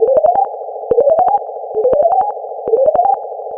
Bleep Warning.wav